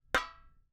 sfx_walk_metal_0.mp3